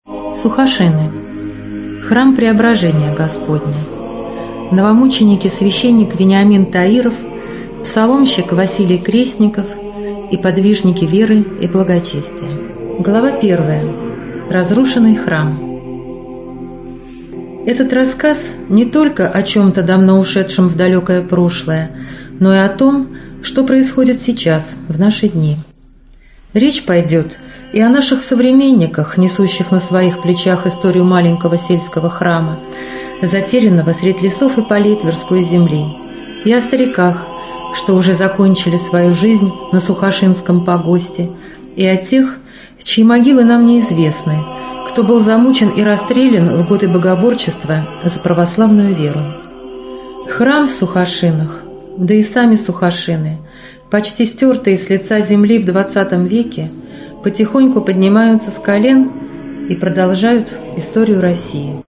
Аудиокнига Сухошины | Библиотека аудиокниг